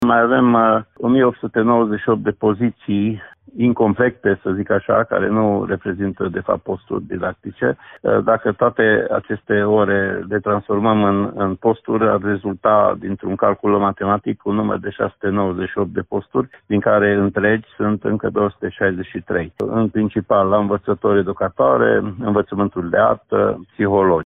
Inspectorul școlar general al județului Mureș, Ștefan Someșan, a arătat că, totuși, situația este mai bună decât anul trecut, iar o parte dintre ele se vor completa în săptămânile următoare: